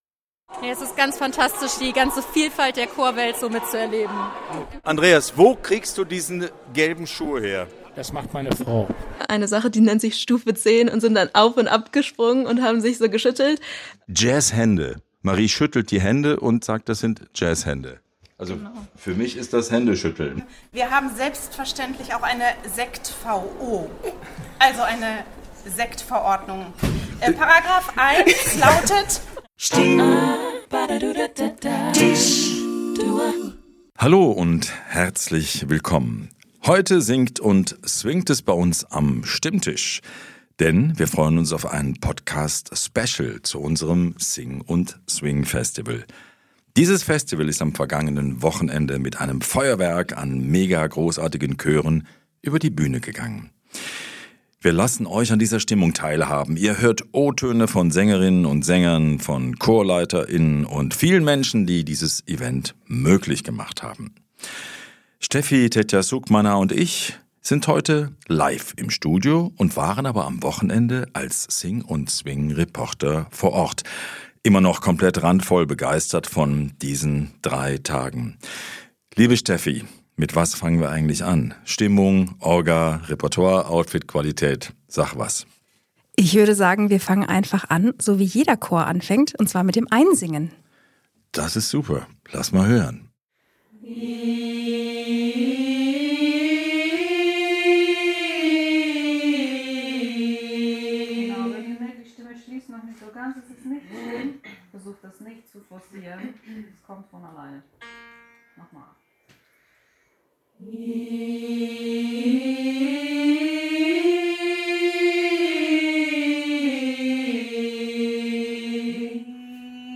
Taucht mit uns ein in die Festival Stimmung unseres SING & Swing Festivals 2025. Eine Menge emotionaler O-Töne, Einblicke hinter die Kulissen, unbekannte Insider Infos und....spürbare Begeisterung!